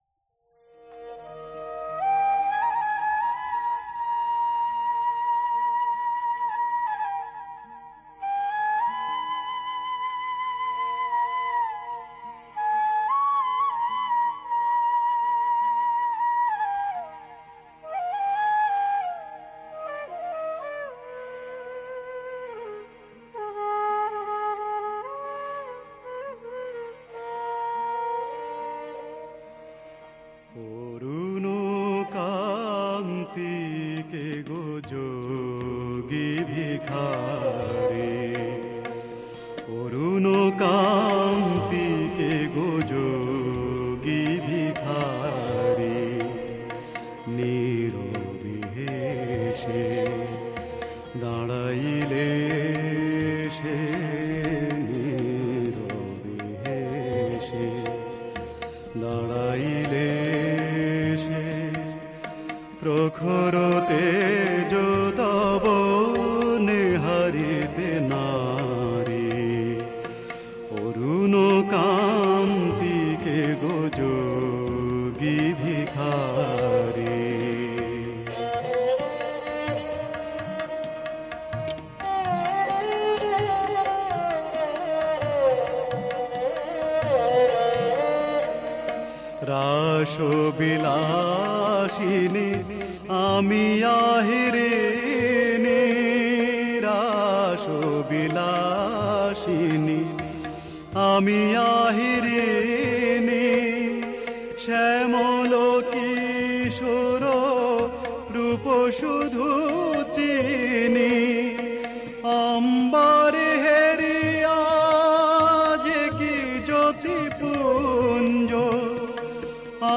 বিষয়: আহির ভৈরব।